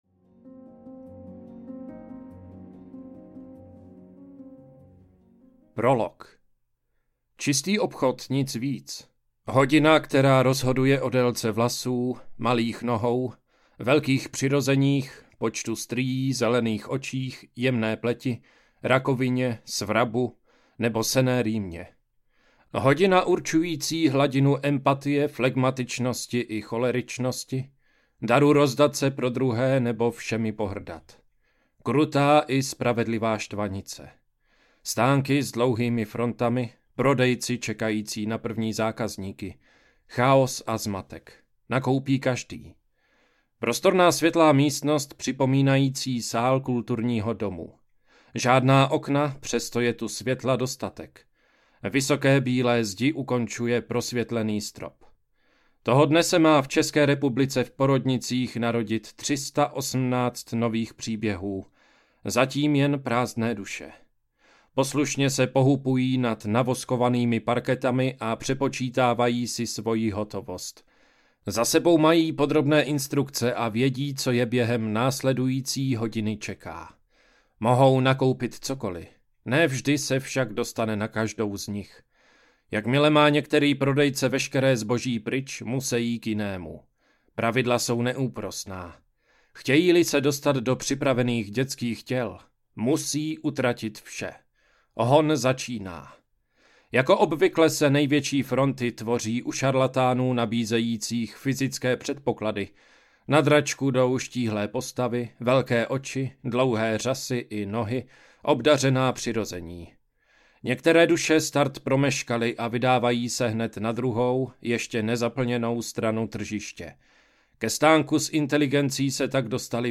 Společnosti audiokniha
Ukázka z knihy